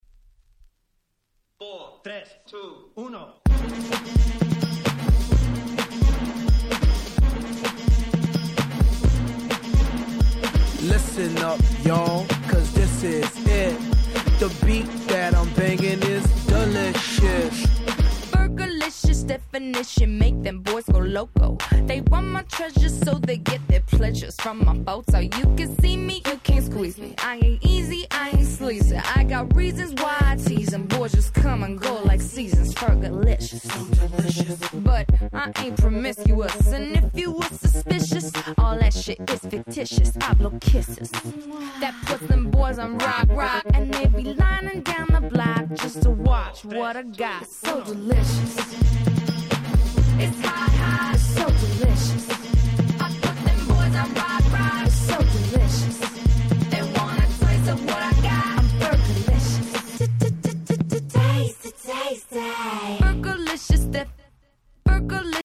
06' Super Hit R&B !!